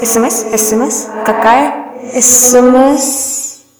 • Качество: 320, Stereo
на смс
голосовые